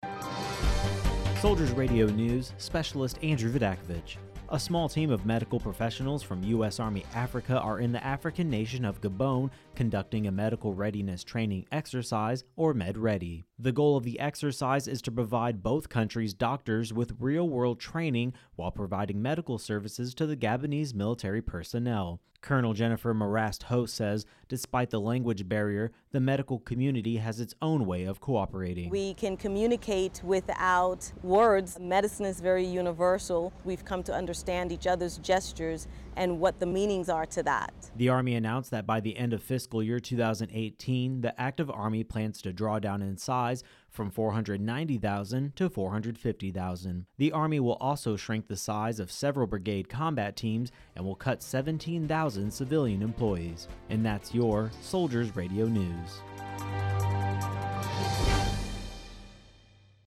Soldiers Radio News